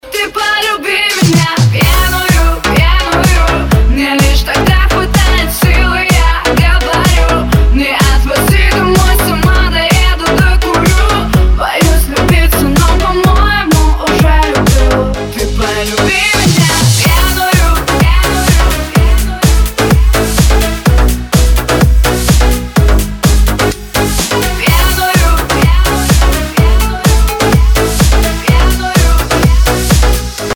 • Качество: 256, Stereo
женский вокал
dance
electro house